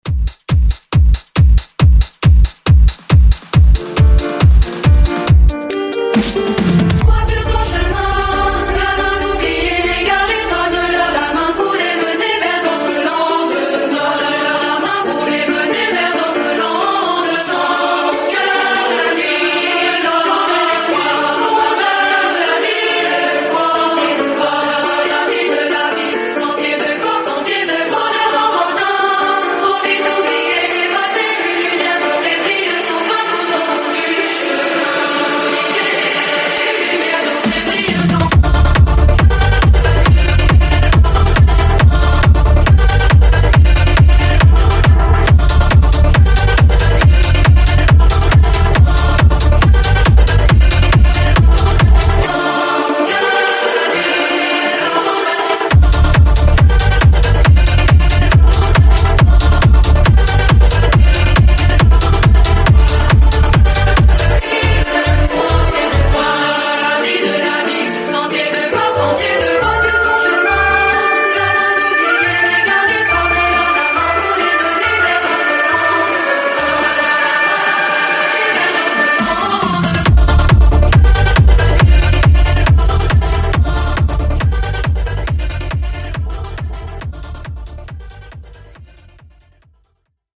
Genre: DANCE
Dirty BPM: 130 Time